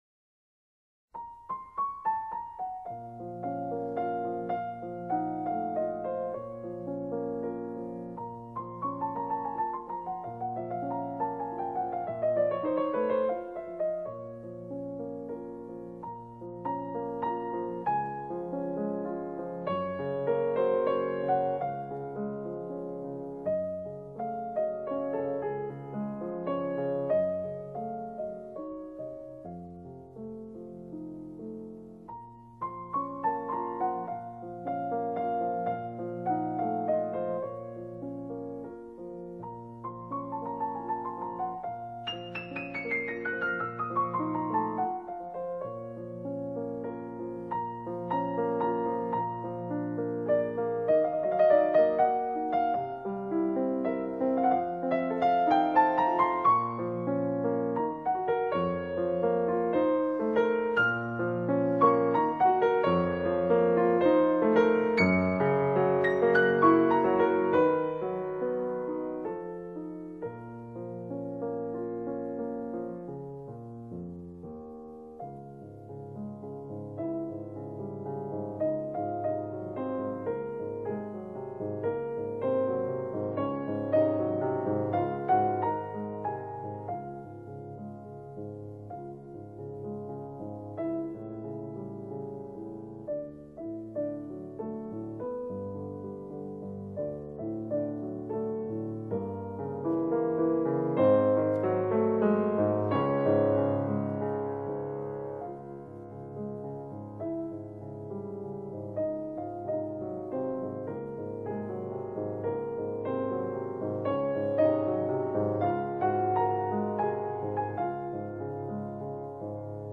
两个版本比较起来，“全集”版的更温暖， 而单版的更明亮。
降B小调 OP.9.1 甚慢板